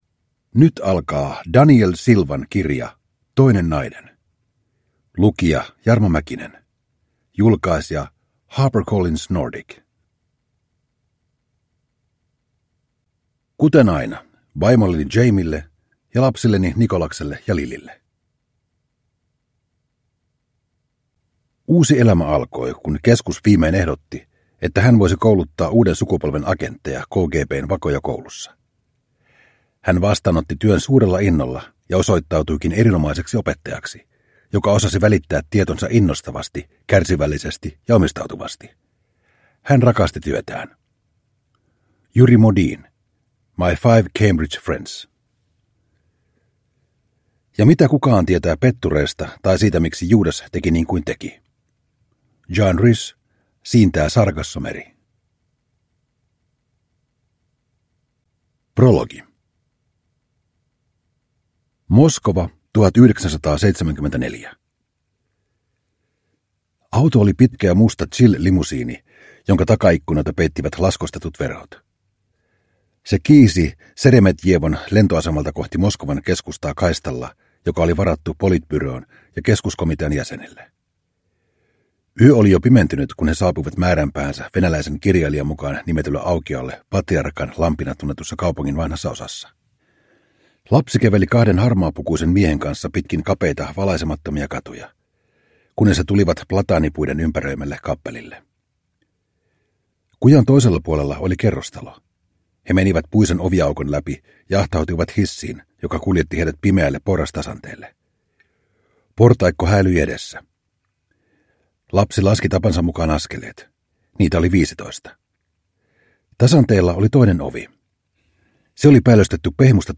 Toinen nainen – Ljudbok – Laddas ner
Produkttyp: Digitala böcker